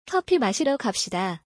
コピ マシシダ